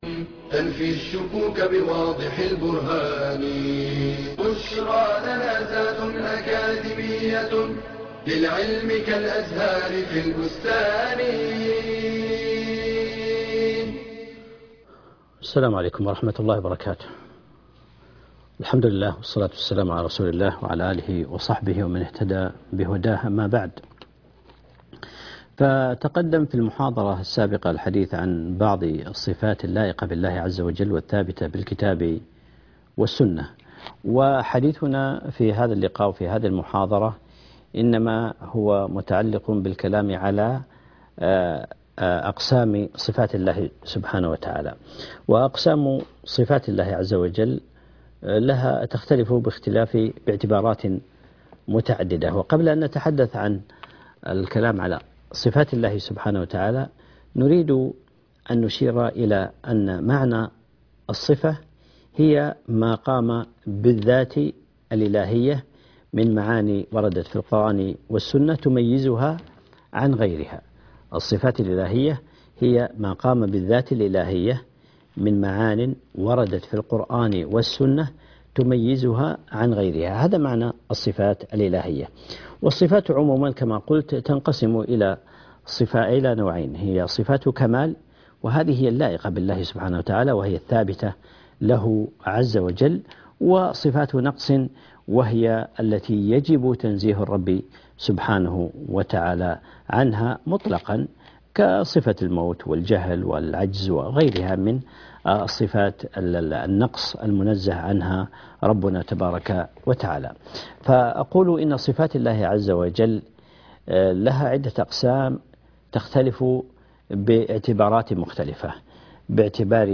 المحاضرة السادسه عشرة